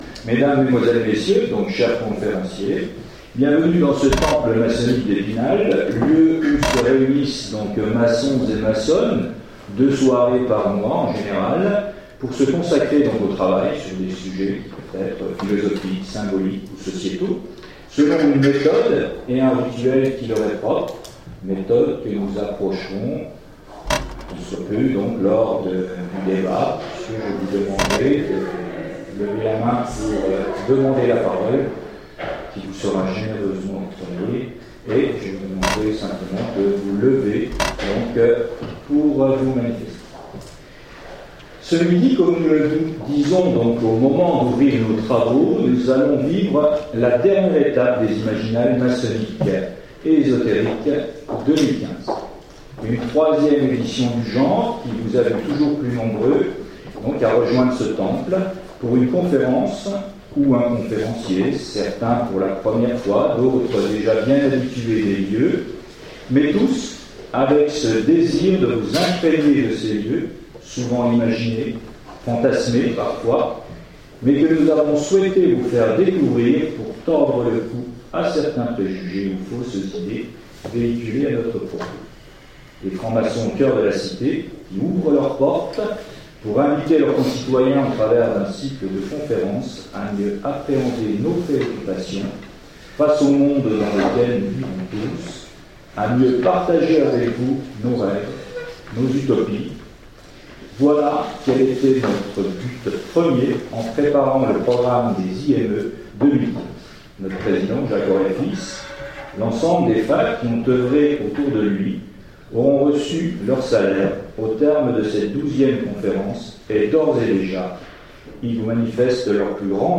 Imaginales 2015 : Conférence Les utopies à l'épreuve des sciences